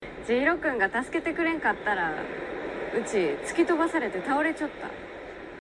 由於柳瀬小時候在四國的高知長大，因此本劇大部分角色皆使用當地方言——土佐方言。
底下聽劇中小暢怎麼說。